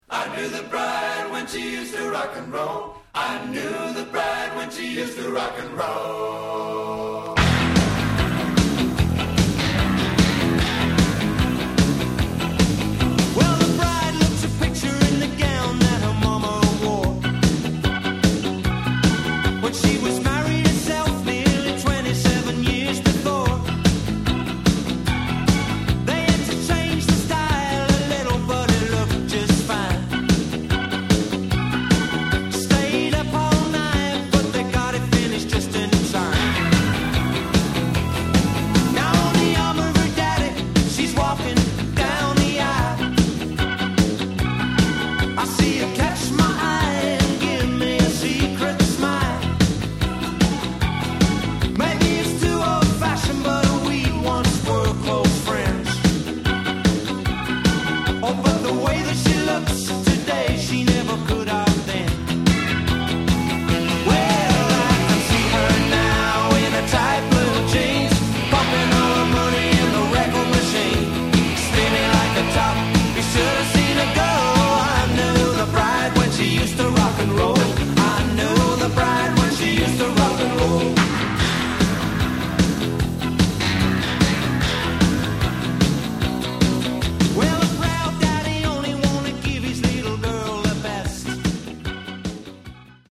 The A side is specially edited from a 4:25 album track.